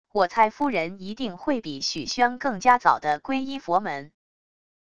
我猜夫人一定会比许宣更加早的皈依佛门wav音频生成系统WAV Audio Player